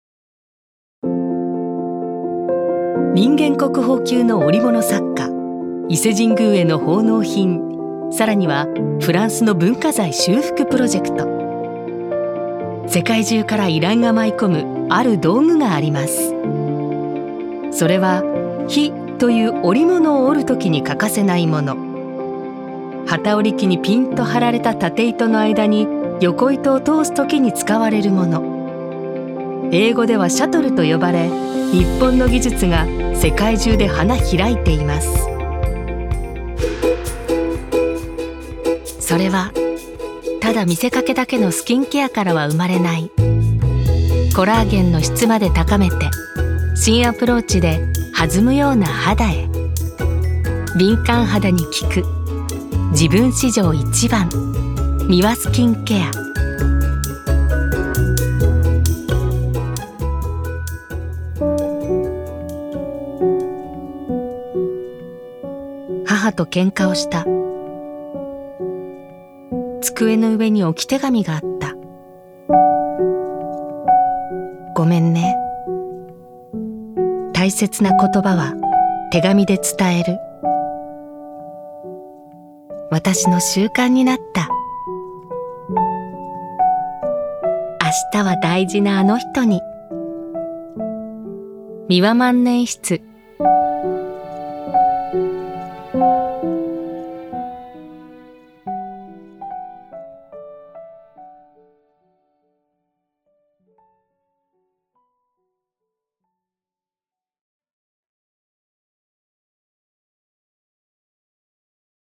ボイスサンプル
化粧品CM・車CM・企業VP
日本の伝統(硬め）・化粧品CM（ウィスパーっぽく）・万年筆CM（エモーショナル）